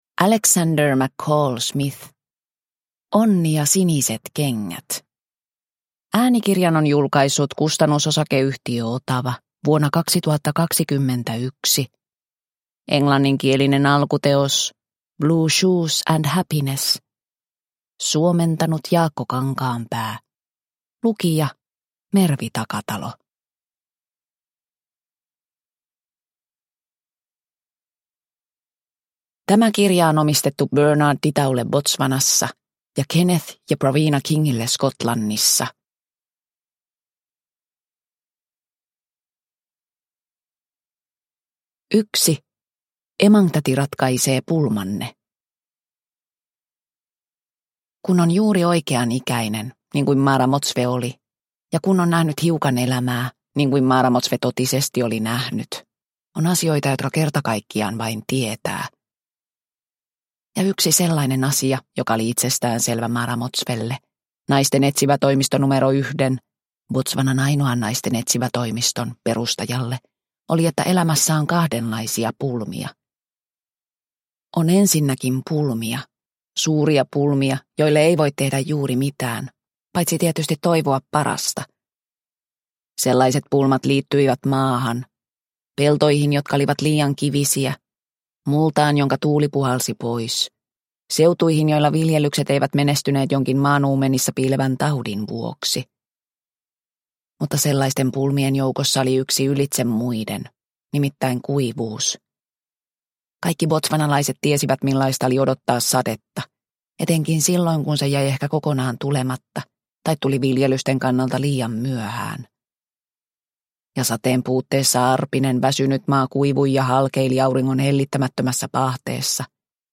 Onni ja siniset kengät – Ljudbok – Laddas ner